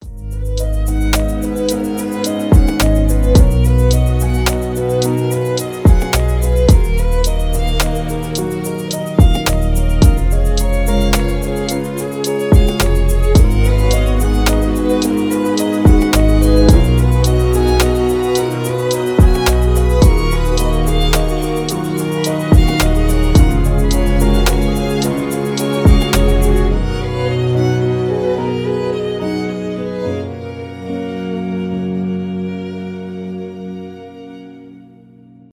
скрипка
инструментальные , без слов , красивая мелодия